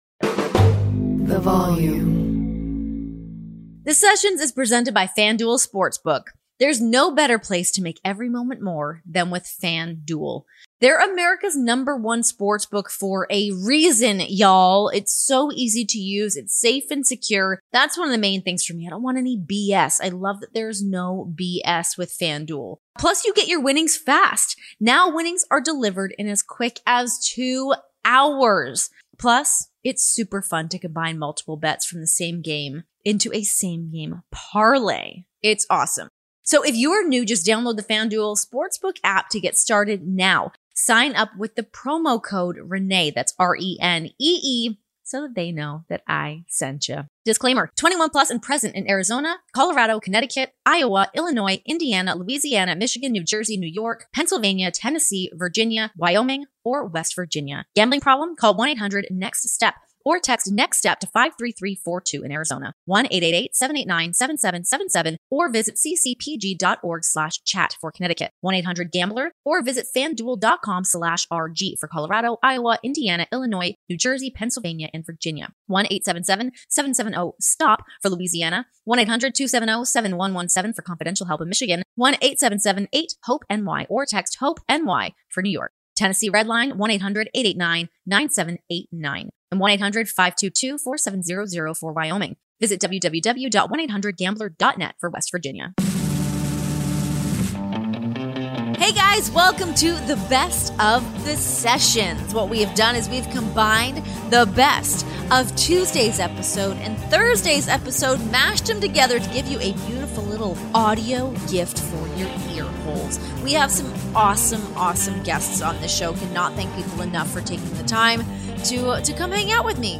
Headliner Embed Embed code See more options Share Facebook X Subscribe Relive the best of the Sessions this week, featuring highlights from Renee's chats with Miro and Saraya .